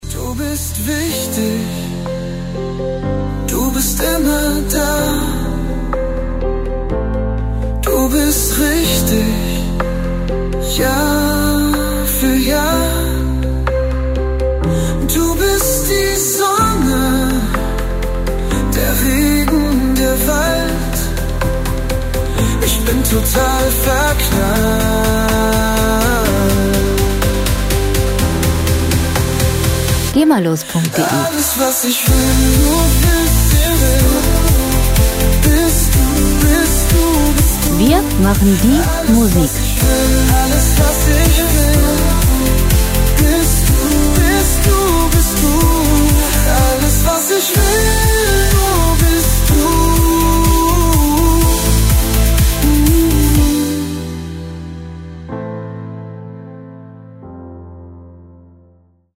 Musikstil: Deutschpop
Tempo: 62 bpm
Tonart: A-Moll
Charakter: gefühlvoll, intensiv
Instrumentierung: Piano, Synthesizer, Gitarre, Gesang